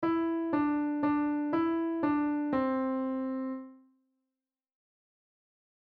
On the piano, play Mary Had A Little Lamb
E D D E D C